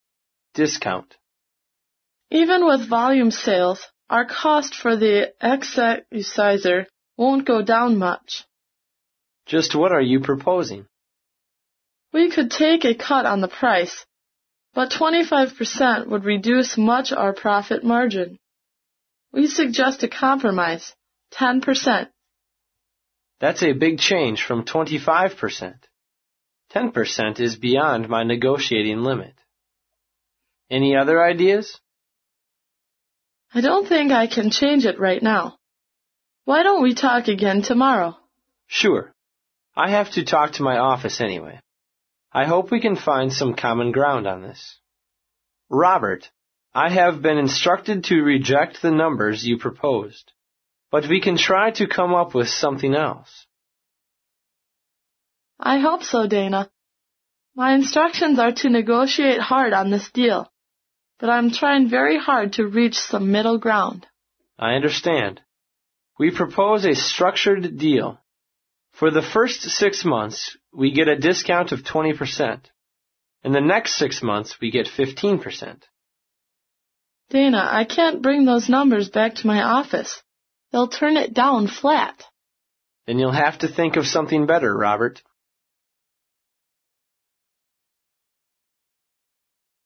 在线英语听力室外贸英语话题王 第63期:折扣的听力文件下载,《外贸英语话题王》通过经典的英语口语对话内容，学习外贸英语知识，积累外贸英语词汇，潜移默化中培养英语语感。